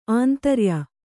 ♪ āntarya